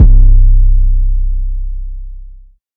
metro siz tm sonny 808.wav